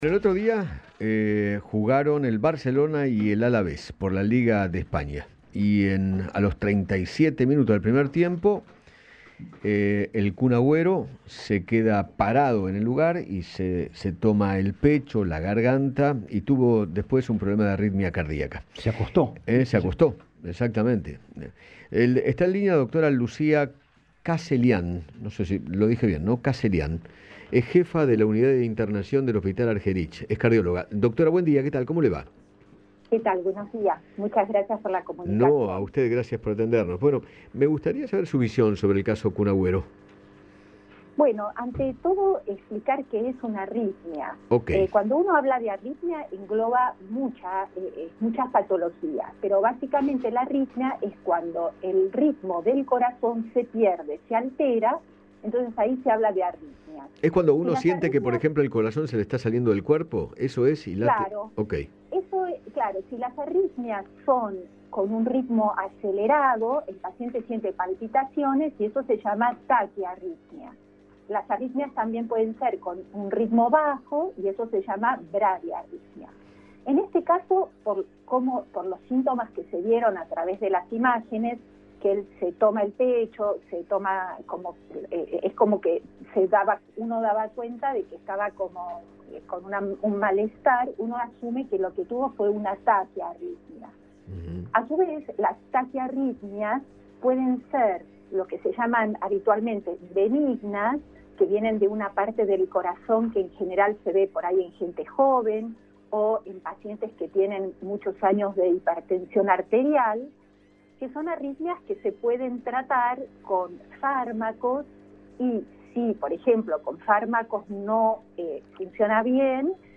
¿Que-es-una-arritmia-cardiaca-Radio-Rivadavia-AM630.mp3